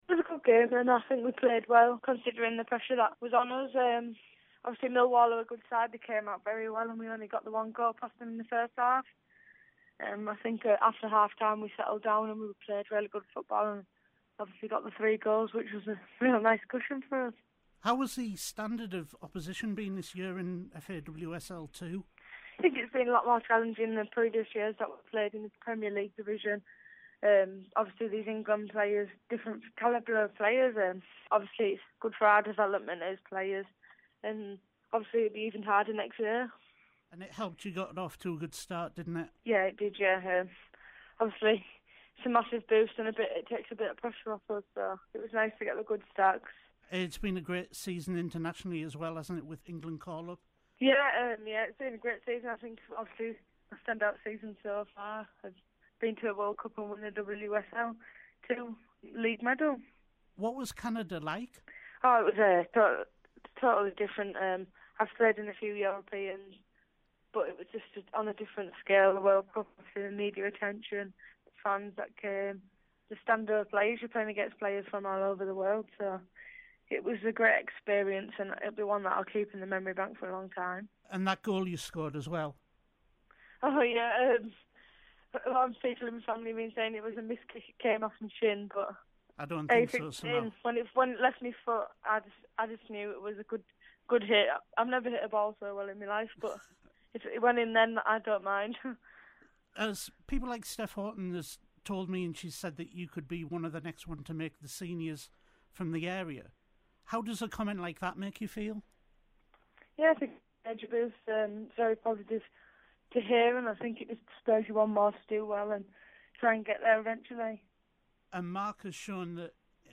I spoke to Beth Mead from SAFC Ladies after her side gained promotion to FAWSL 1